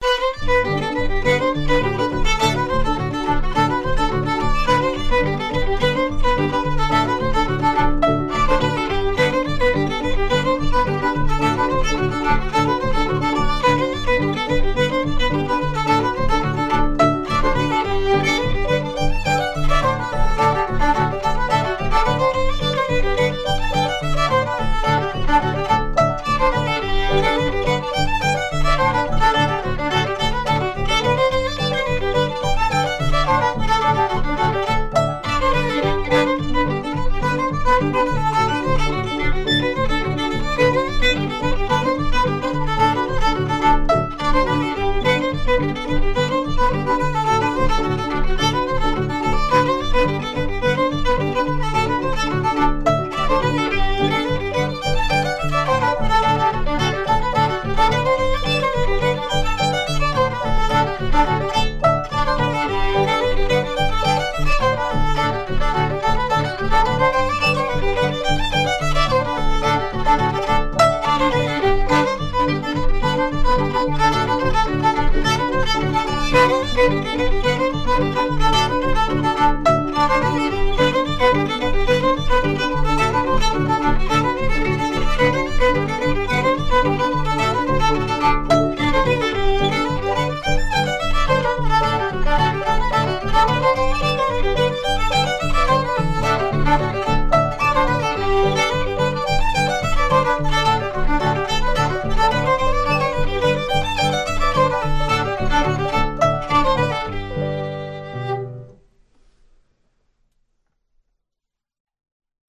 West Point Reel